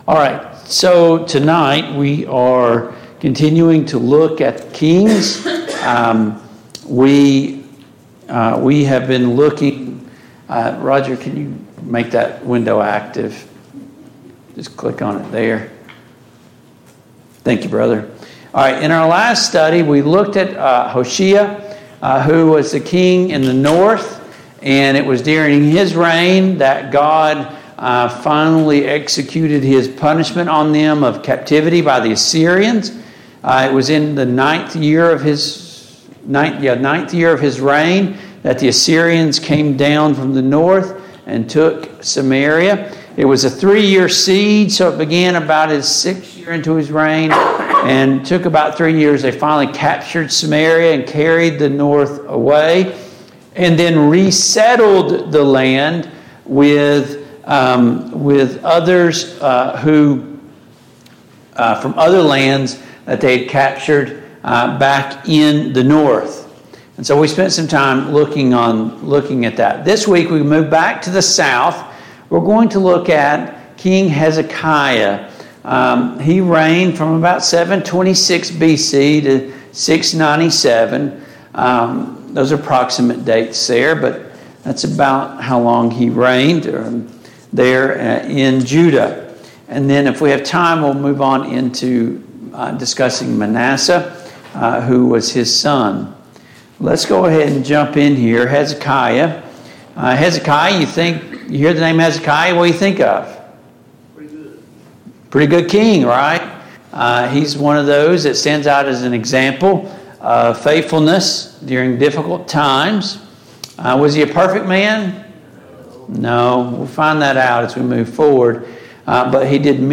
The Kings of Israel and Judah Passage: 2 Kings 18, 2 Chronicles 29, 2 Chronicles 30, 2 Chronicles 31 Service Type: Mid-Week Bible Study